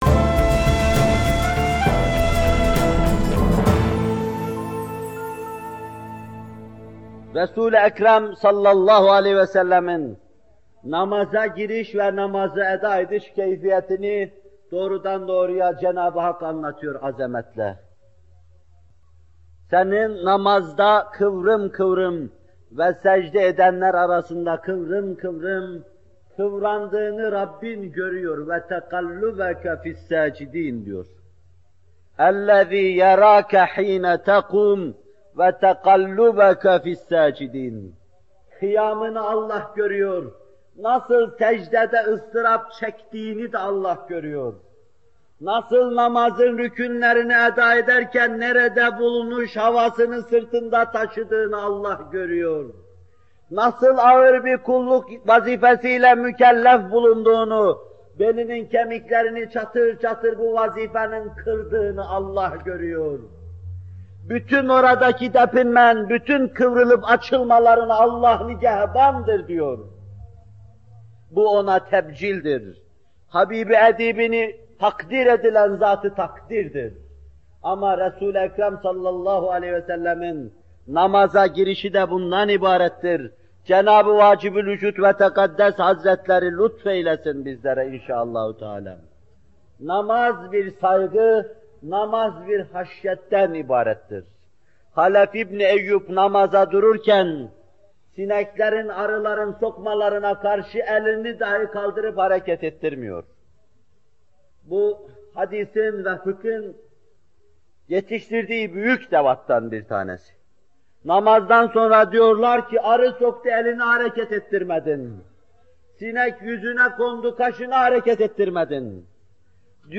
Bu bölüm Muhterem Fethullah Gülen Hocaefendi’nin 15 Eylül 1978 tarihinde Bornova/İZMİR’de vermiş olduğu “Namaz Vaazları 5” isimli vaazından alınmıştır.